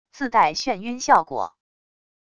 自带眩晕效果wav音频